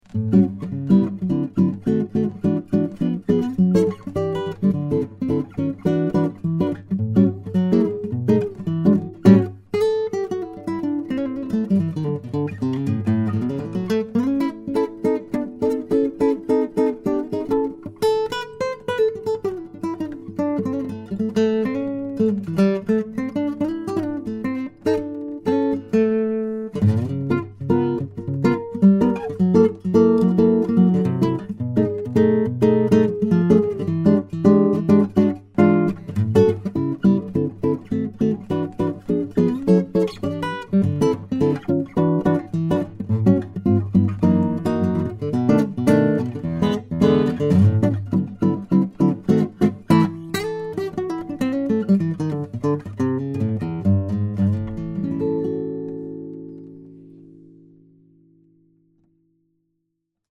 New Traphagen MP-S D'Aquisto Style Crossover Maple/Spruce - Dream Guitars